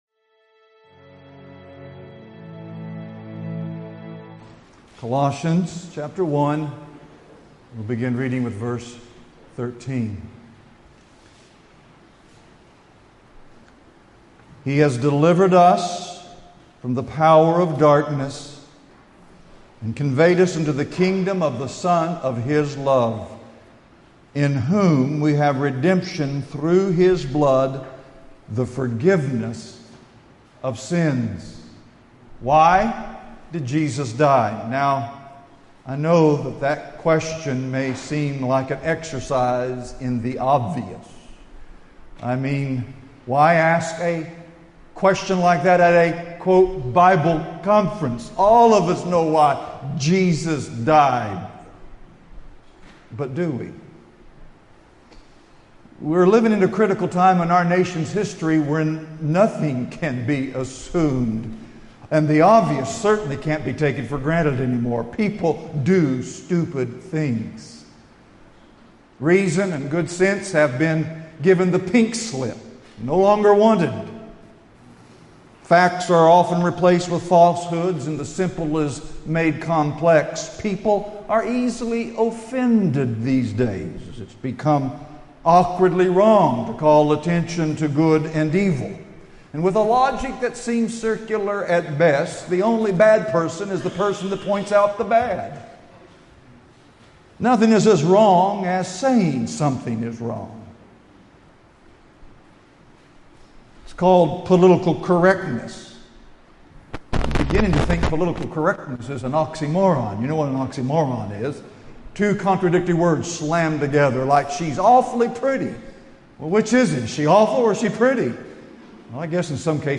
Preached in Portland, Maine at the 2016 Fellowship Conference New England
2016 Category: Full Sermons Topic